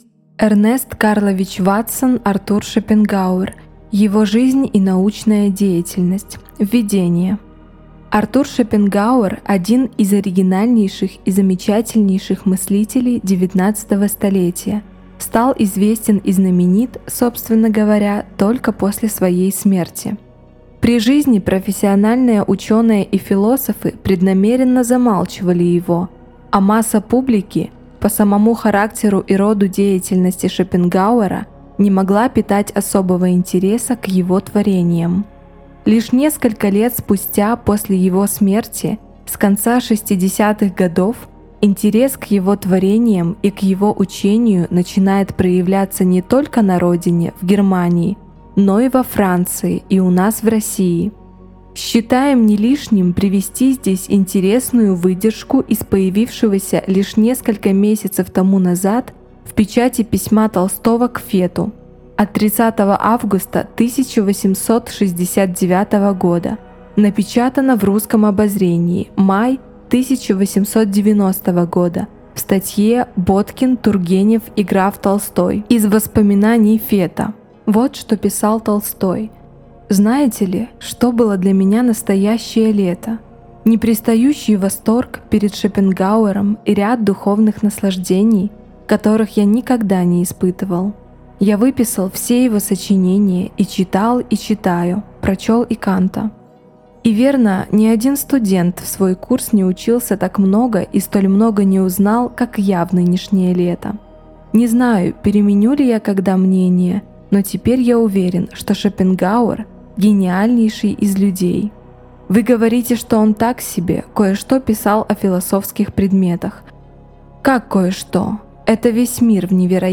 Аудиокнига Артур Шопенгауэр. Его жизнь и научная деятельность | Библиотека аудиокниг